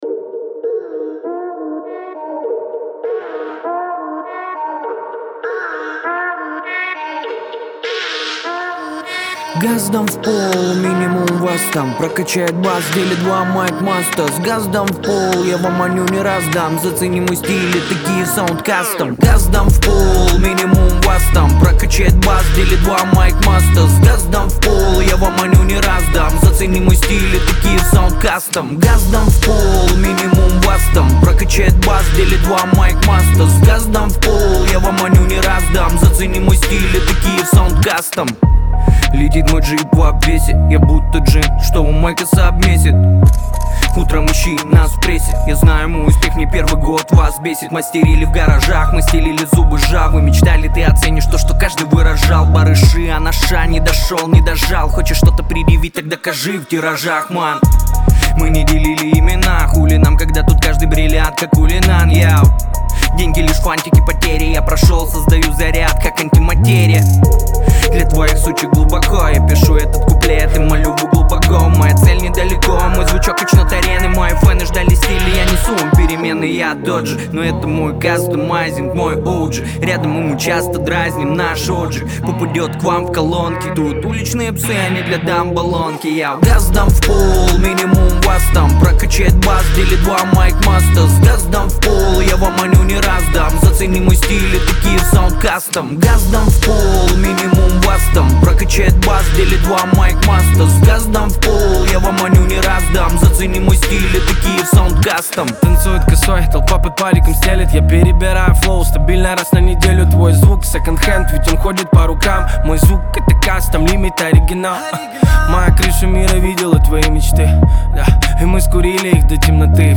это динамичная композиция в жанре хип-хоп